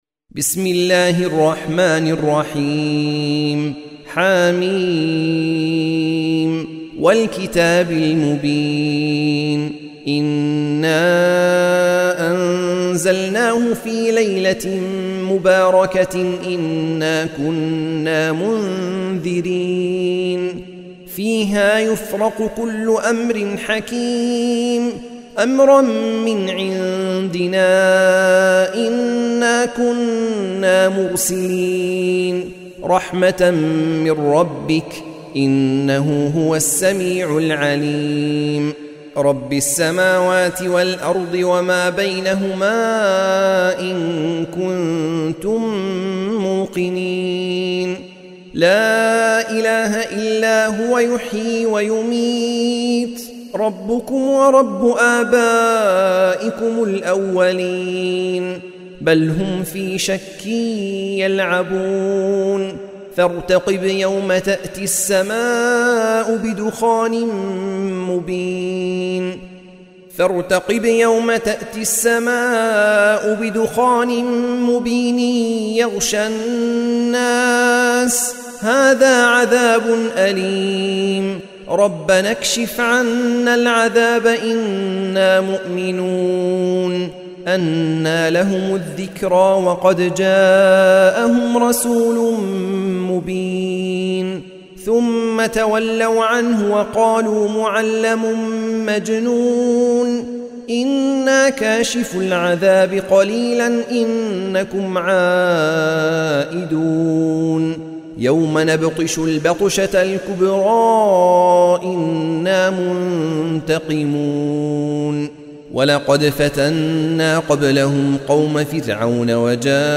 سورة الدخان مكية عدد الآيات:59 مكتوبة بخط عثماني كبير واضح من المصحف الشريف مع التفسير والتلاوة بصوت مشاهير القراء من موقع القرآن الكريم إسلام أون لاين